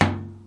冲击、撞击、摩擦 工具 " 塑料桶咚咚 1
Tag: 工具 工具 崩溃 砰的一声 塑料 摩擦 金属 冲击